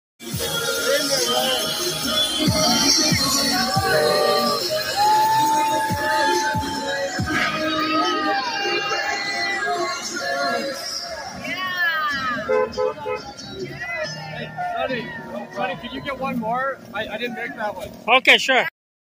Diese Bilder stammen vom 17.Juli 2022 in Toronto, Kanadas Bürger unterstützen die holländischen Bauern....